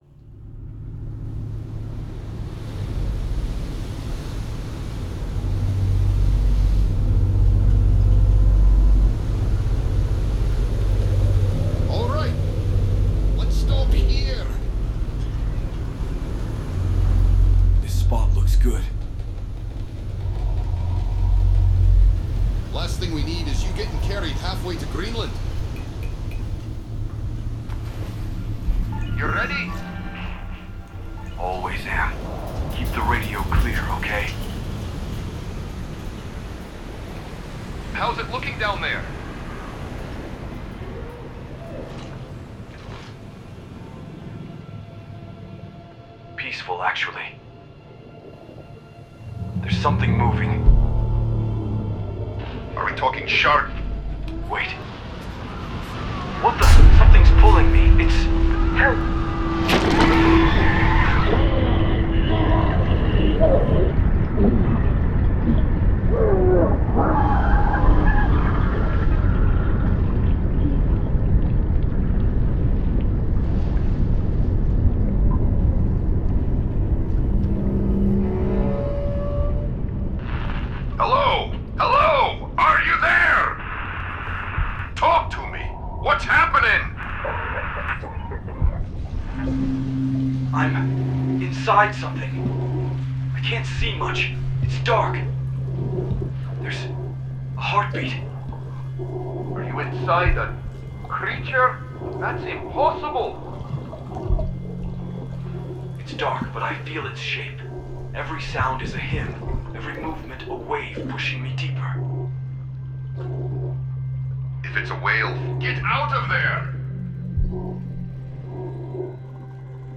• Format: Dolby Atmos composition
• Web version: binaural stereo
The player uses a binaural render of the Atmos composition, so the piece can be experienced on headphones.
The piece is built as a physical listening experience, using spatial movement to make loneliness, pressure and claustrophobia felt.
The whale’s heartbeat, sloshing movement, blood currents and underwater space are placed as objects around the listener, while the diver moves through the height layer inside the whale’s body.
Cello fragments, breath, radio noise and whale song create the contrast between organic sound and electronic disruption. Silence and masking are used to hide, disturb and tighten the tension as the radio communication breaks down.
The work ends in an explosive exhalation through the whale’s blowhole and an open cello passage.
The binaural version is made to preserve depth, placement and movement from the Atmos composition.
swallowed-by-the-deep-binaural.mp3